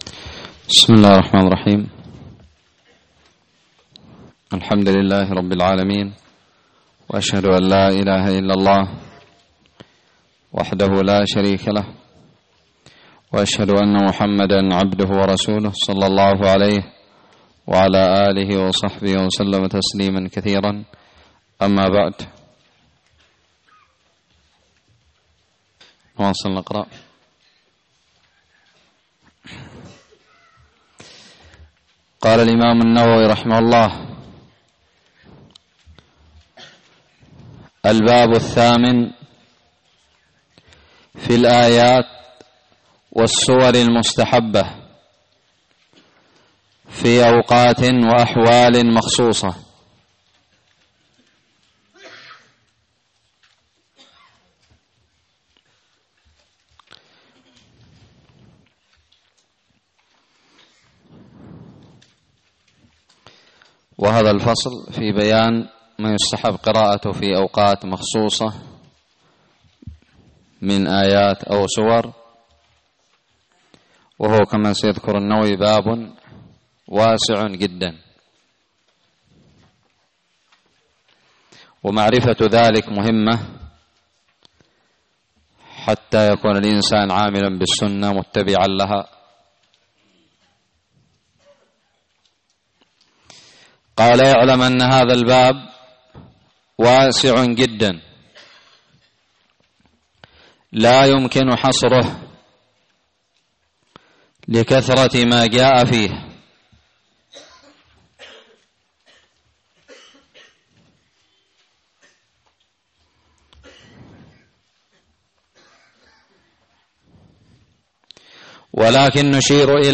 الدرس الثالث والثلاثون من شرح كتاب التبيان في آداب حملة القرآن
ألقيت بدار الحديث السلفية للعلوم الشرعية بالضالع